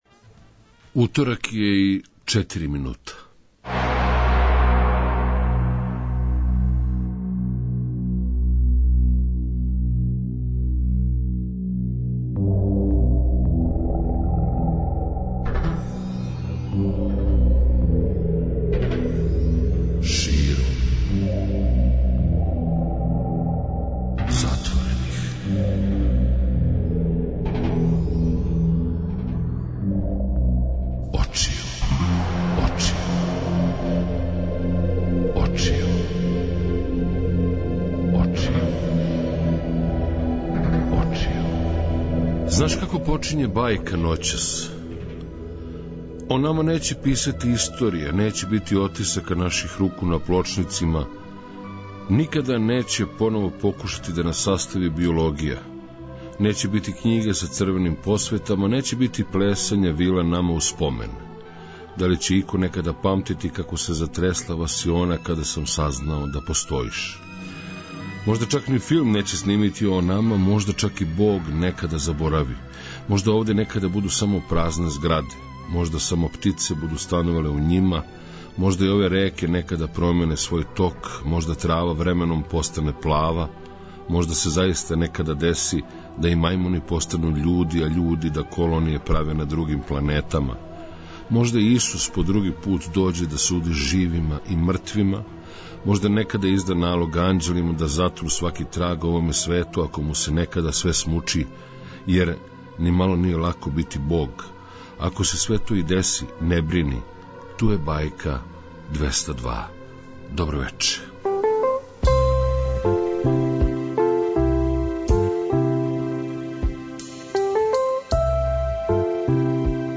А вечерашња, ноћашња бајка биће баш музичка.